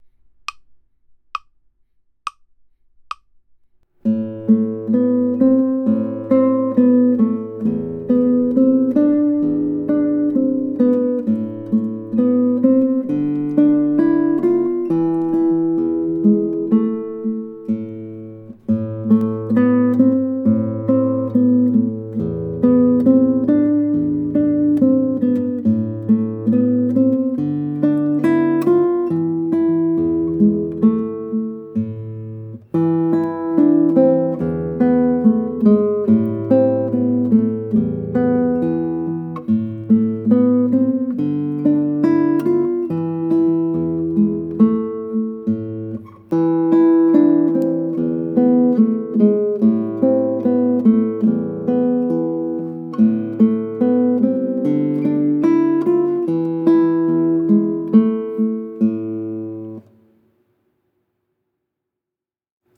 Your first solo, Etude in A Minor, uses a melody and accompaniment texture: melody played on the treble strings—first, second and third strings—and accompaniment played on the bass strings—fourth, fifth and sixth strings.
The target tempo of Etude in A Minor is andante—moderately slow.
Etude in A Minor | Antonio Curriera Cano (1811-97) | This performance is faster than the rendition in the "how to" video above.